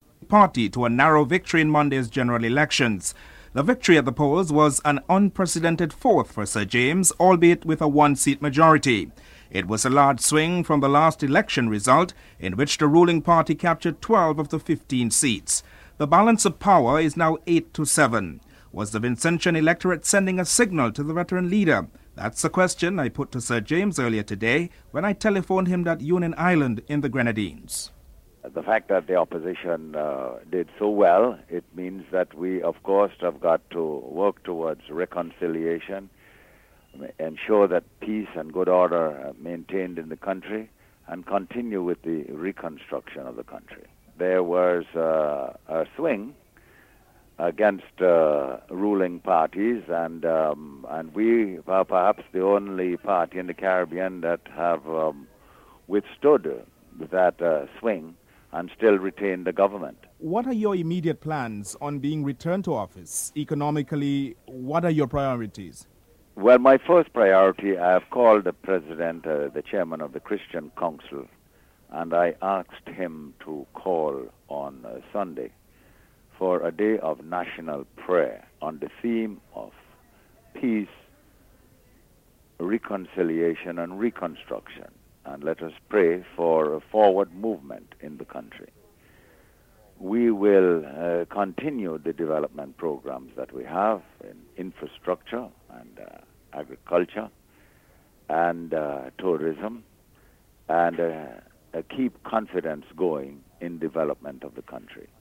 2. In Trinidad and Tobago deep concerns have emerged over falling oil prices. Energy Minister Finbar Ganga comments on the issue (05:00-06:49)